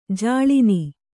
♪ jāḷini